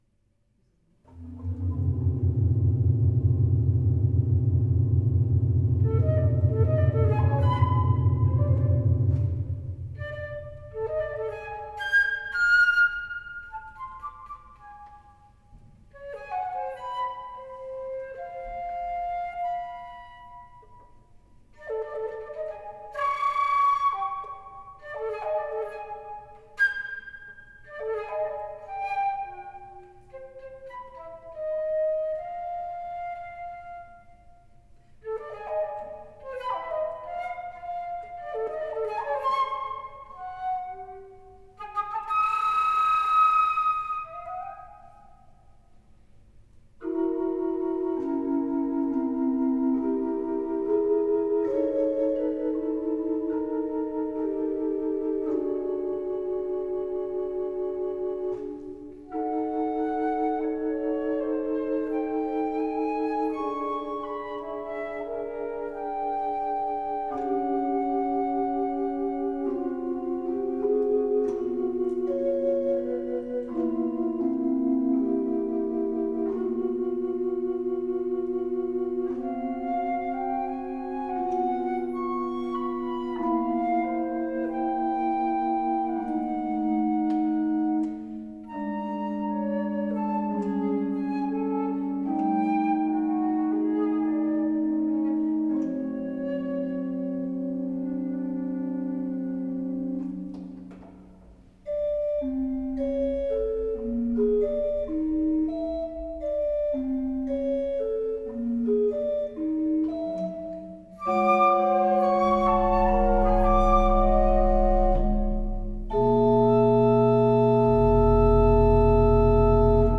Flötenmusik